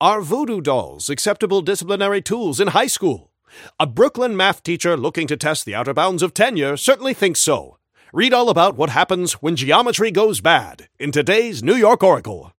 Newscaster_headline_84.mp3